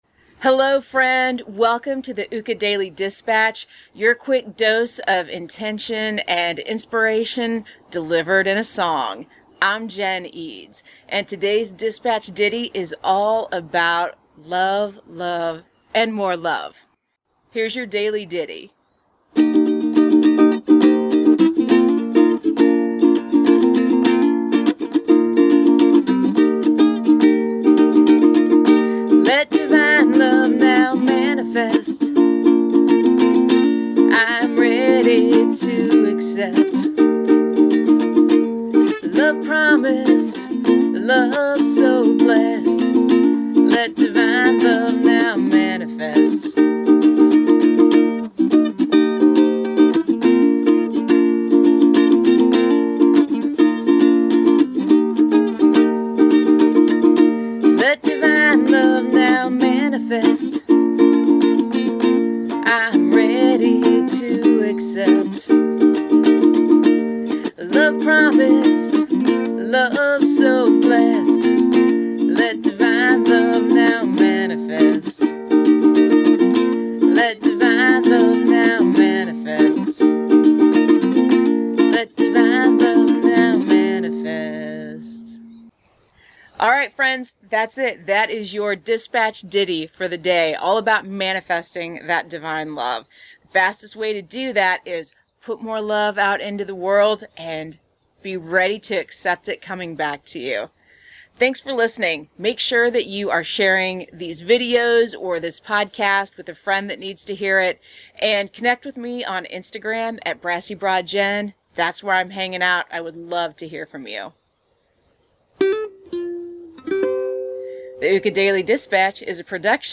This mantra song for manifesting love was inspired by the book "The Dynamic Laws of Prosperity" by Catherine Ponder.